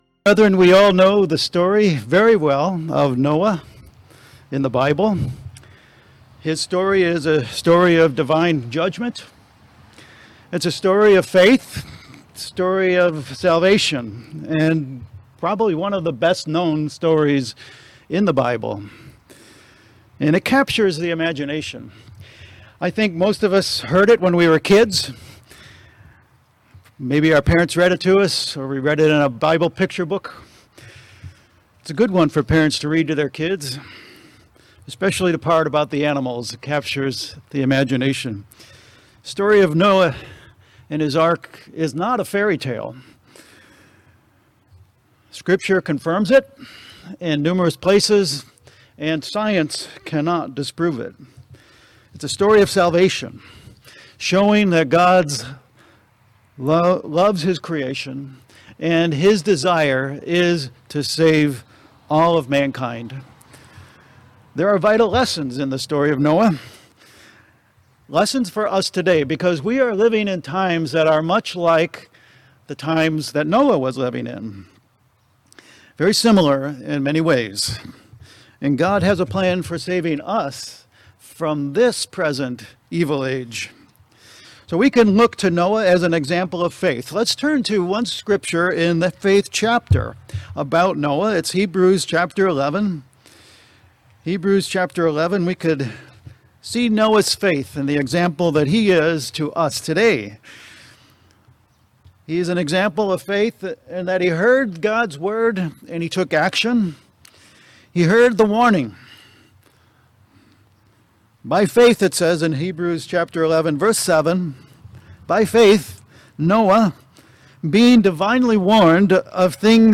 Given in Bangor, ME Saratoga Springs, NY Southern New Hampshire Worcester, MA